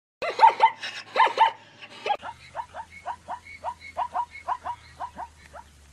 Zebra Sound Effect Pro Sounds Jcvxk8rdz Dw (audio/mpeg)
ZEBRA